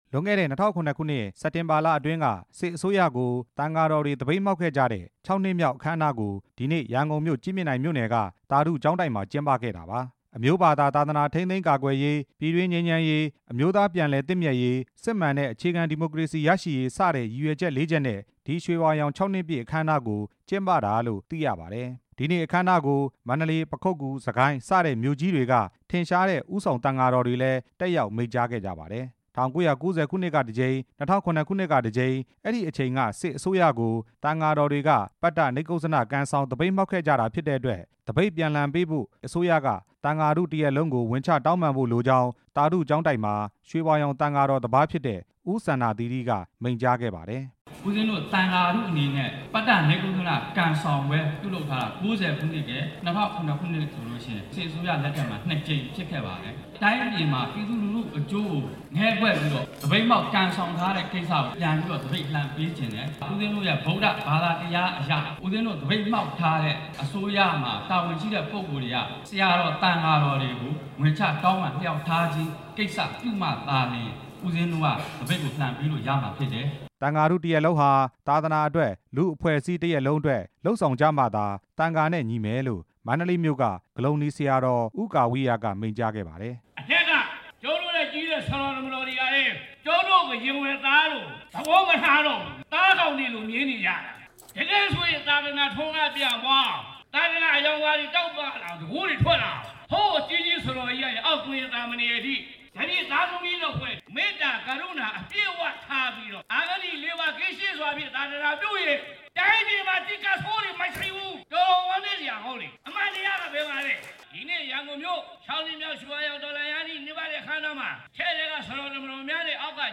ရွှေဝါရောင်အရေးအခင်း နှစ်ပတ်လည်နေ့ အခမ်းအနား တင်ပြချက်